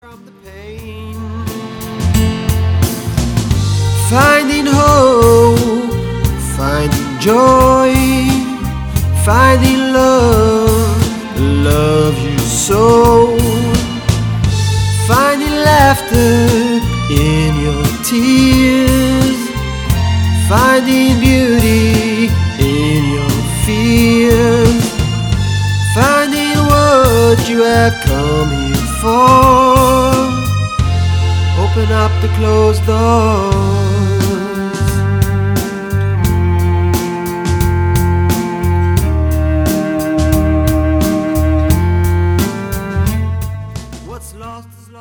Contemporary Jewish music with a rock/folk vibe.